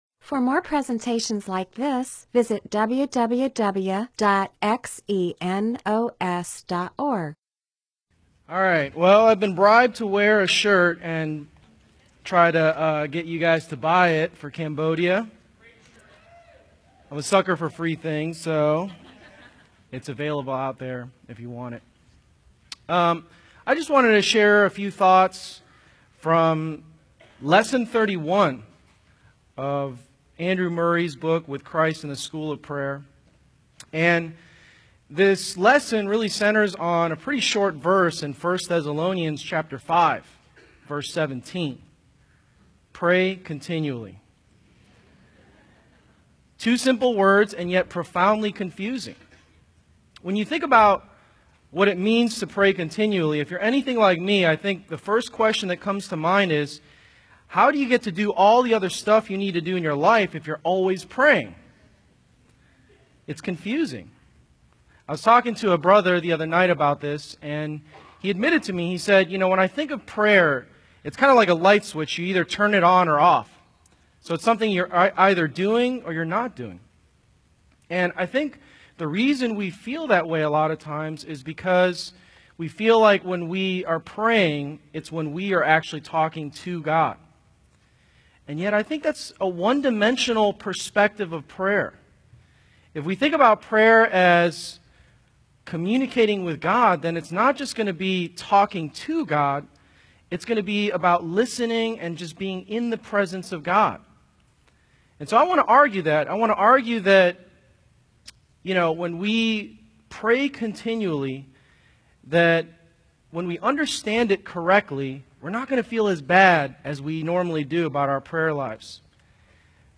MP4/M4A audio recording of a Bible teaching/sermon/presentation about 1 Thessalonians 5:17.